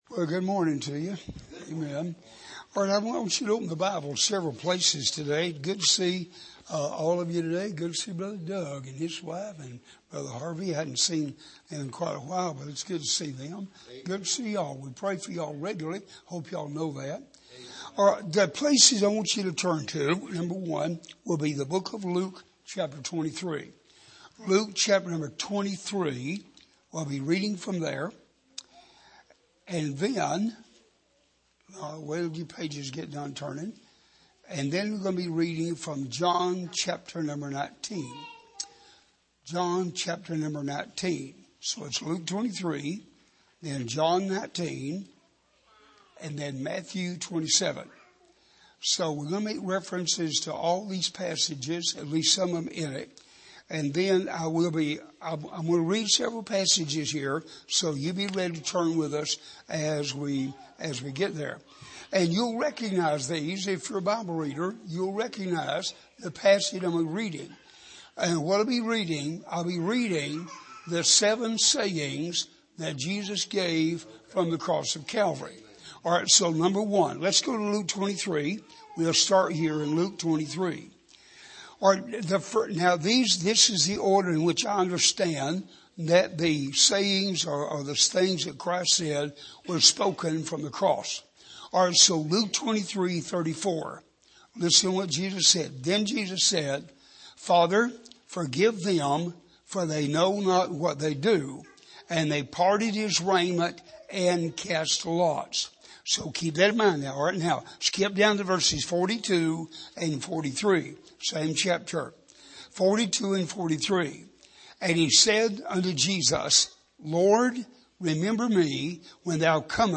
Sermon Archive
Here is an archive of messages preached at the Island Ford Baptist Church.